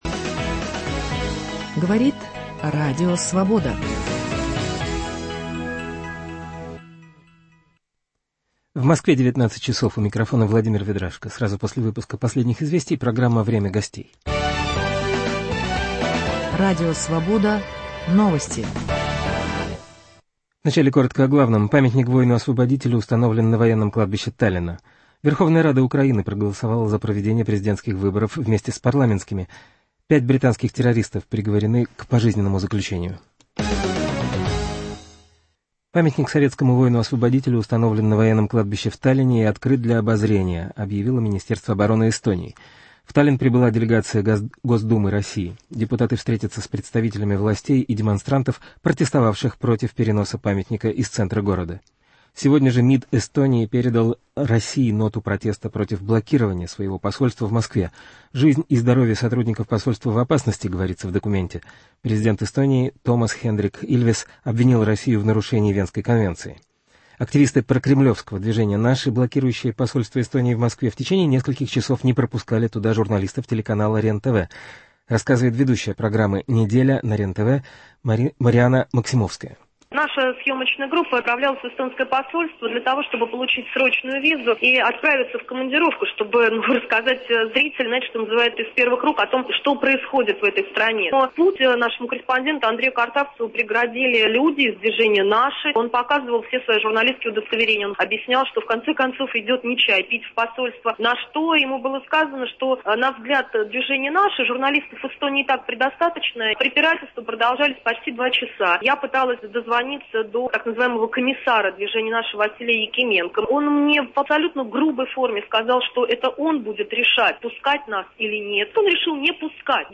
В гостях у Анны Качкаевой ректор Государственного университета Высшей школы экономики, член общественной палаты Ярослав Кузьминов.